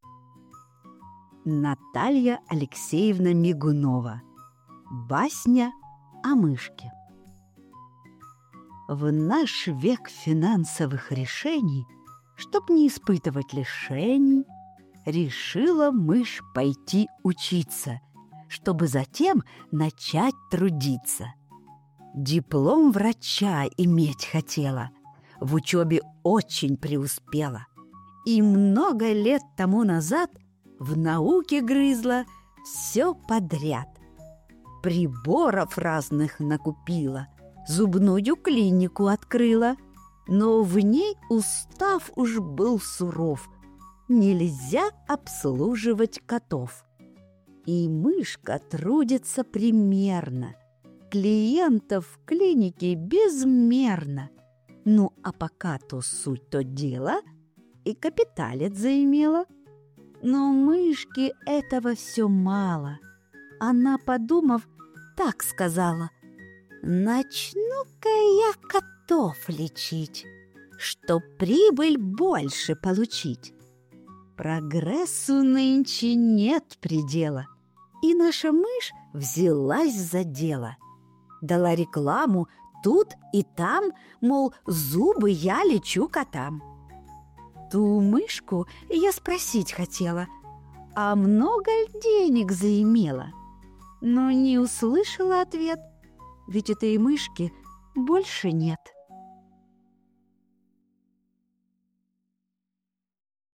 Басня о Мышке (аудиоверсия)